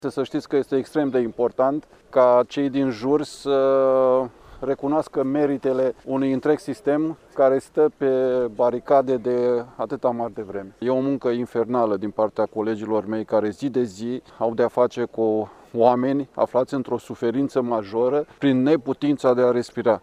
Manifestările din acest an prilejuite de Ziua Națională a României s-au desfășurat, la Iași, pe pietonalul Ștefan cel Mare în fața statuii Regelui Ferdinand.